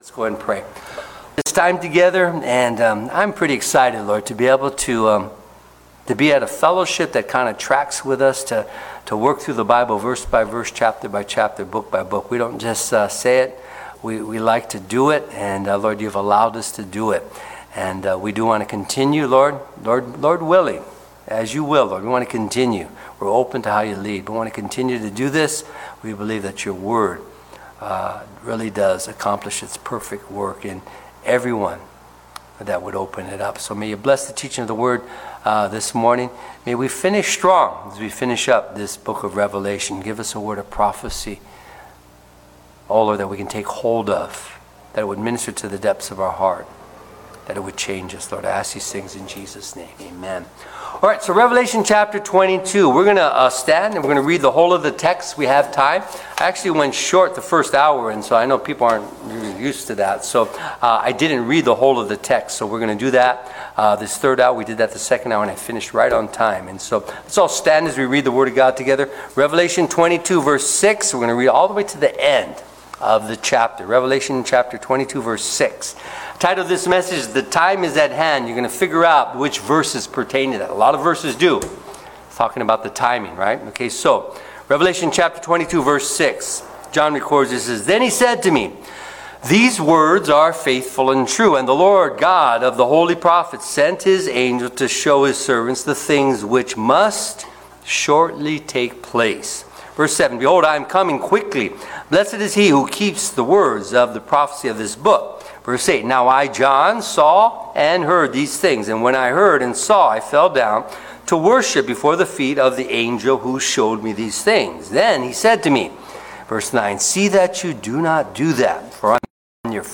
Sermons | Calvary Chapel Echo Park